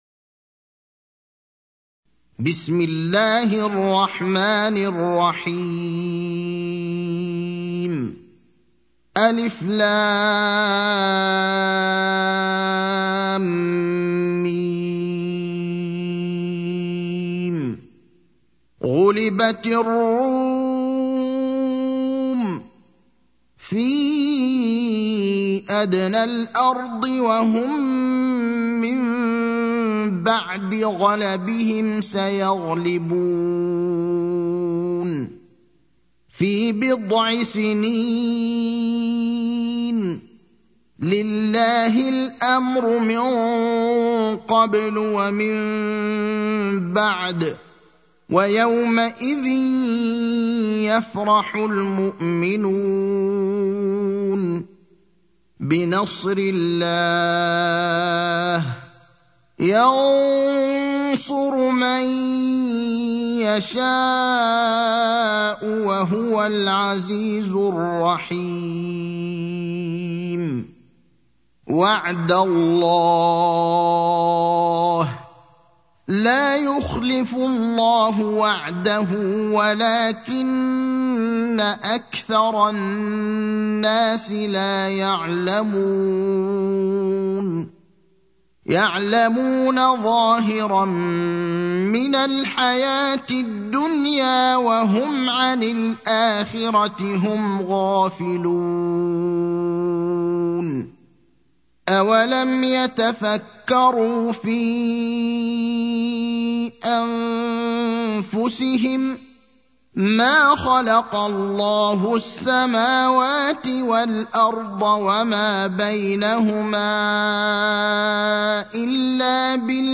المكان: المسجد النبوي الشيخ: فضيلة الشيخ إبراهيم الأخضر فضيلة الشيخ إبراهيم الأخضر الروم (30)